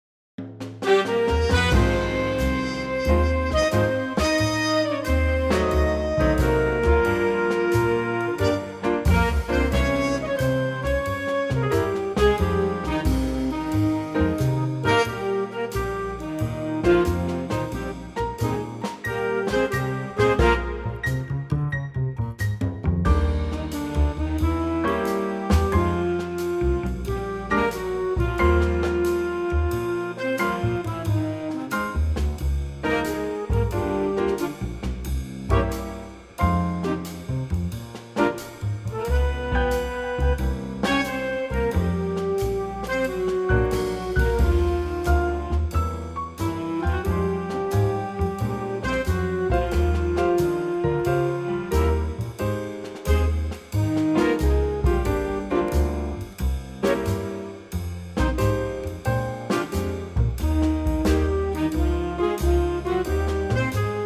key - Eb - vocal range - Eb to Ab
A wonderful mellow Big Band arrangement
main theme only, no verse included and only twice through.
-Unique Backing Track Downloads